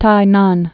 (tīnän)